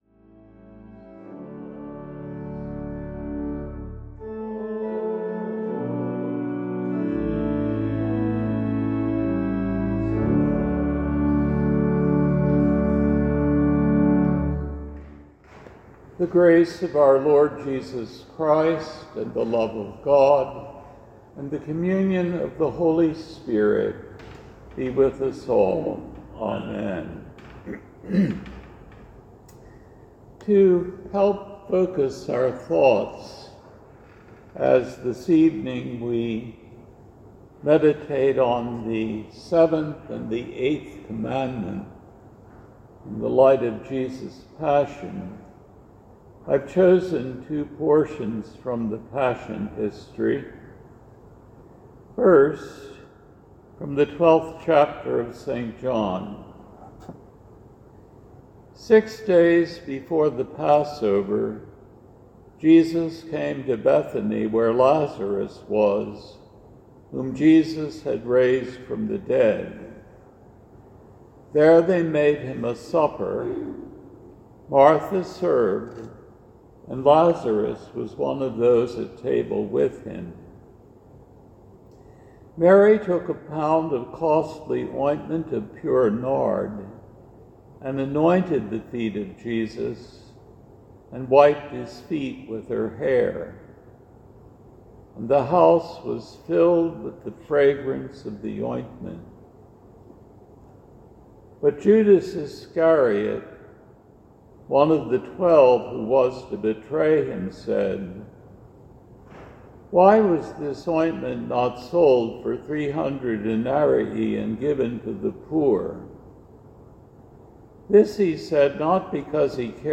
Laetare Midweek Vespers